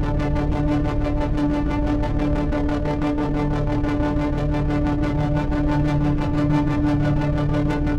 Index of /musicradar/dystopian-drone-samples/Tempo Loops/90bpm
DD_TempoDroneA_90-D.wav